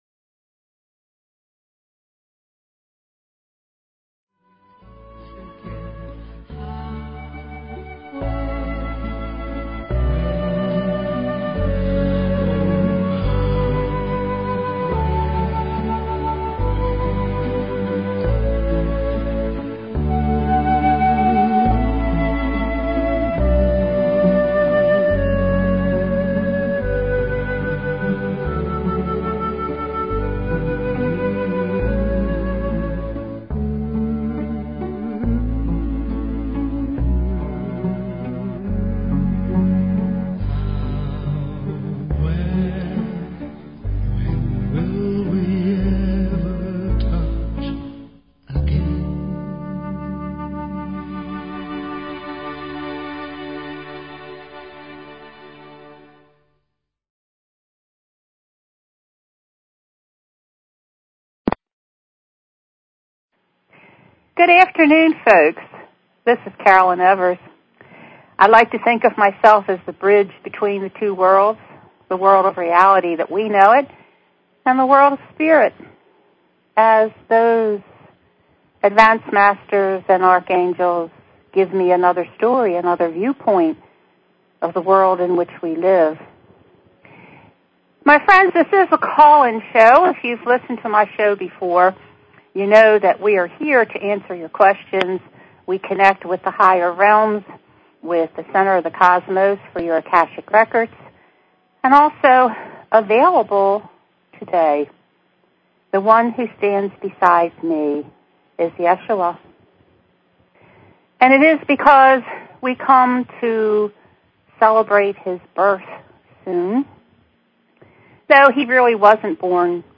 Talk Show Episode, Audio Podcast, The_Messenger and Courtesy of BBS Radio on , show guests , about , categorized as
He explains how we simply need to invite him into an open heart. He also worked with the callers directly.